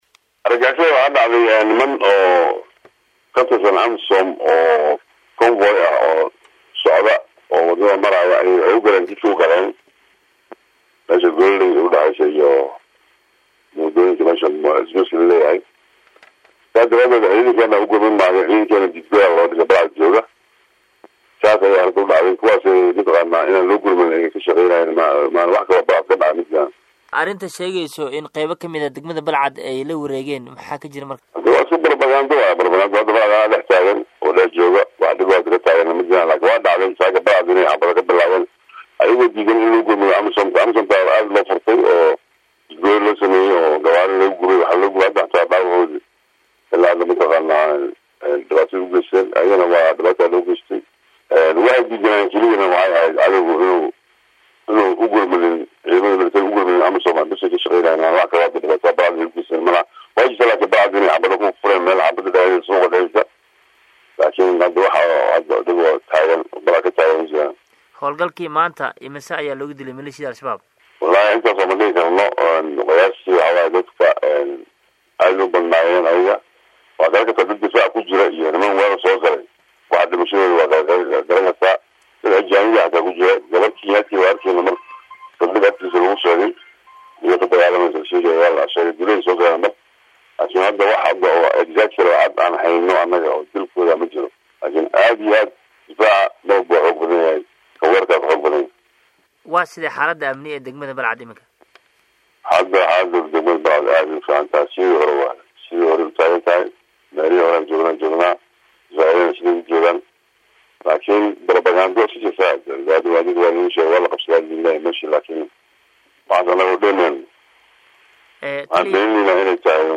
Halkan ka dhageyso wareysiga taliyaha
Taliyaha-qeybta-27aad-ee-CXD-Jeneraal-Maxamuud-Maxamed-Macalin-Koronto-.mp3